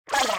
hit_00.ogg